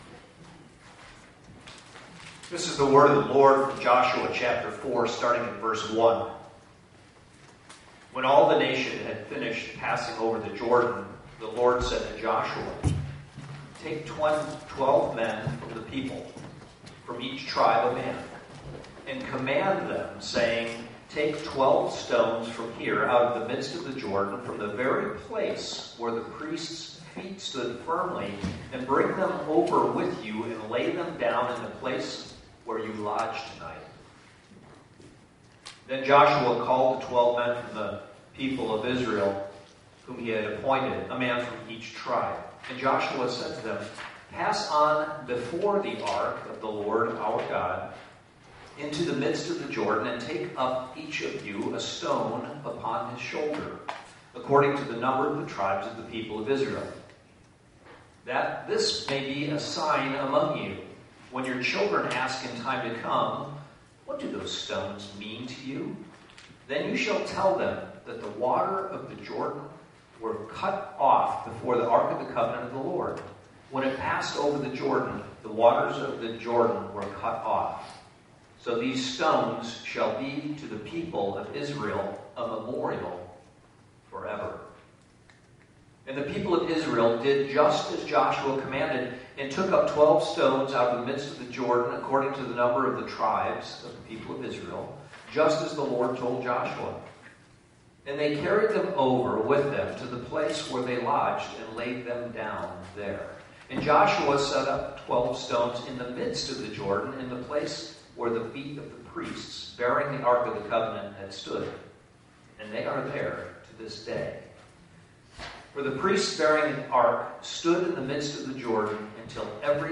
Service Type: Sunday Morning Topics: God is powerful , God's Faithfulness , People of God together